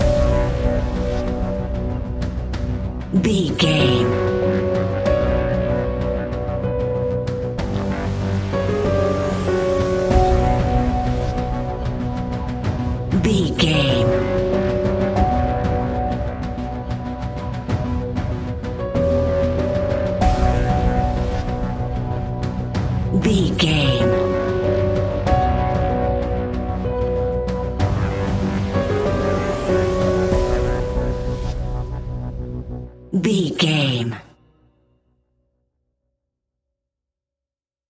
Scary Tense Piano Horror Film Music 30 Sec.
Aeolian/Minor
ominous
eerie
drums
synthesiser
horror piano